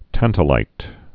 (tăntə-līt)